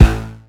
MB Kick (29).wav